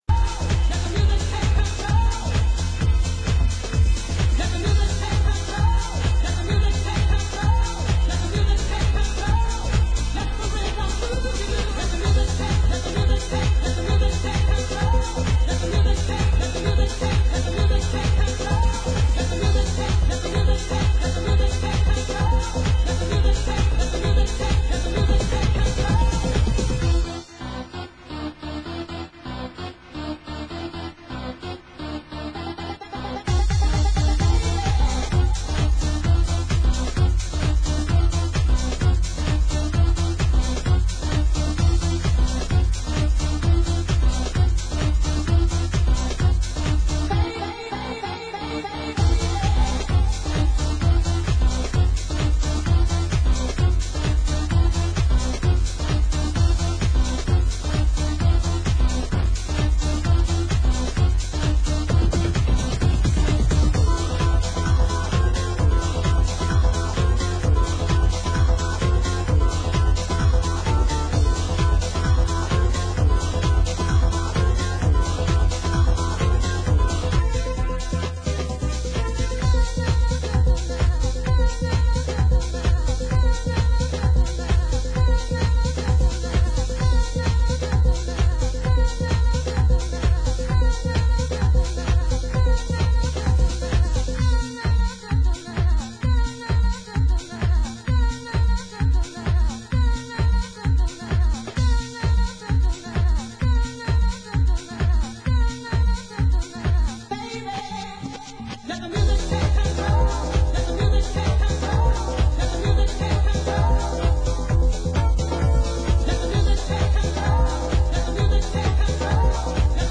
Genre: Euro House